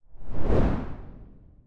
whoosh.mp3